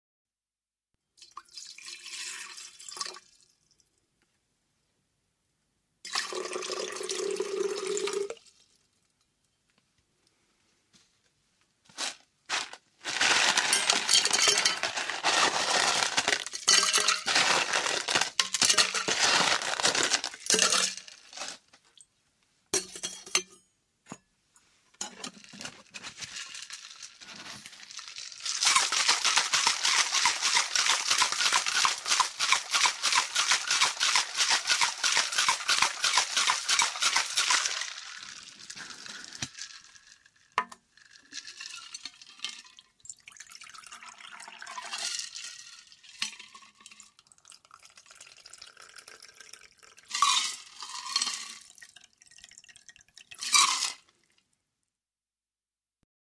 Tiếng Bình lắc Cocktail Shaker Bartender pha chế đồ uống
Thể loại: Tiếng ăn uống
Nhịp lắc kim loại va chạm đầy sôi động gợi cảm giác chuyên nghiệp, không khí náo nhiệt của quán bar.
tieng-binh-lac-cocktail-shaker-bartender-pha-che-do-uong-www_tiengdong_com.mp3